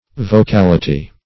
Search Result for " vocality" : The Collaborative International Dictionary of English v.0.48: Vocality \Vo*cal"i*ty\ (v[-o]*k[a^]l"[i^]*t[y^]), n. [Cf. L. vocalitas euphony.] 1.